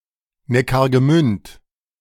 Neckargemünd (German pronunciation: [ˌnɛkaʁɡəˈmʏnt]
De-Neckargemünd.ogg.mp3